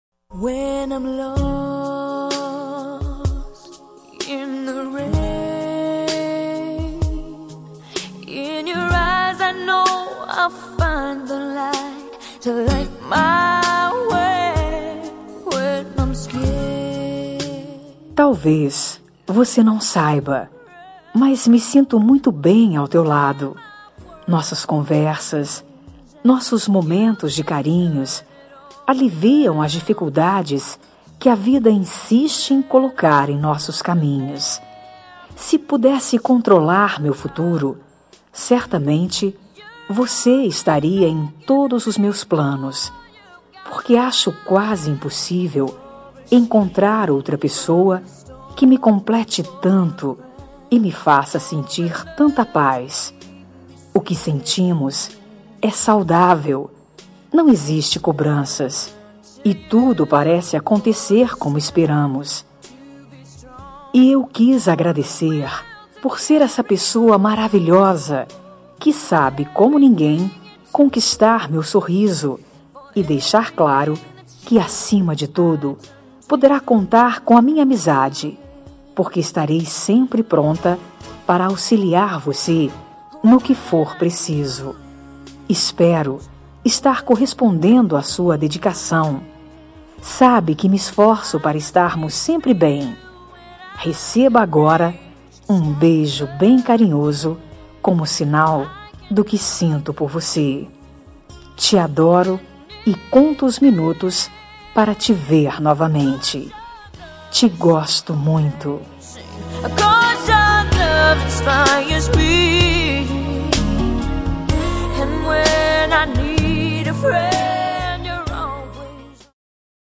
Telemensagem Início de Namoro – Voz Feminina – Cód: 748